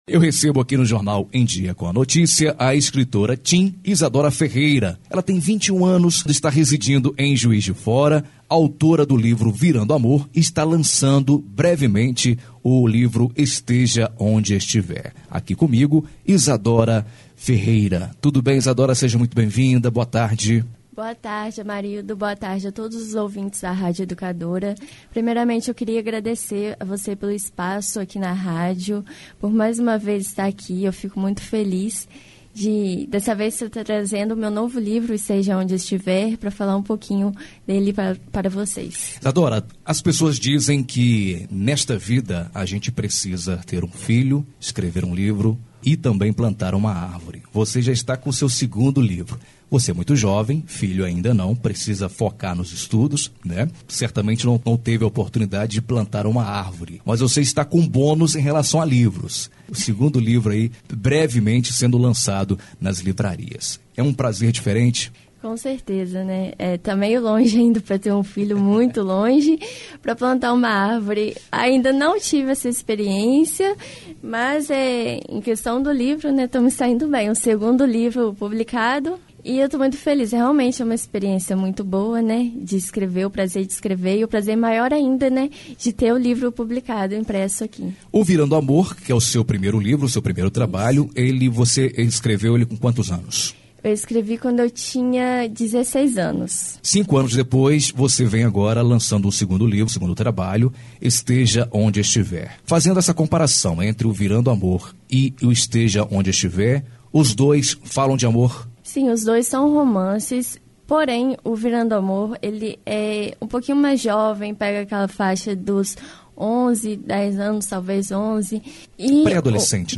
esteve nos estúdios do Jornal Em dia com à Notícia e, contou sobre mais sobre este seu segundo trabalho literário